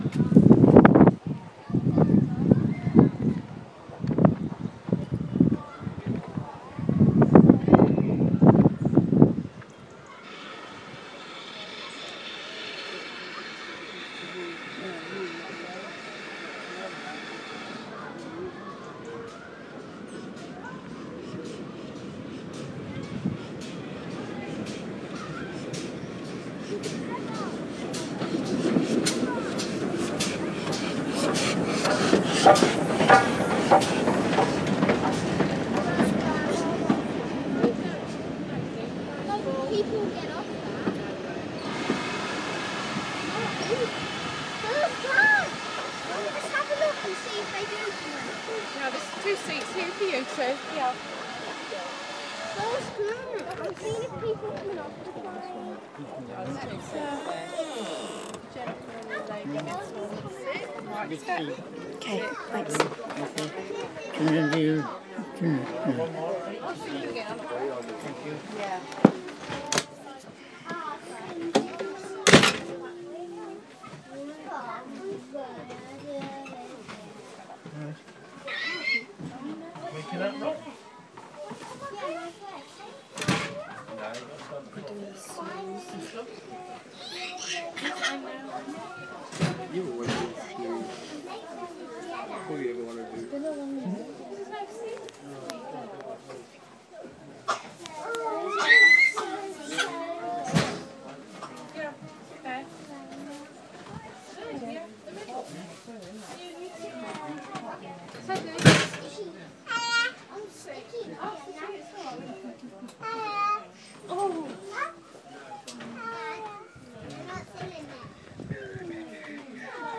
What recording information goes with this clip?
Catching train at Goodrington Sands. From outside train, then in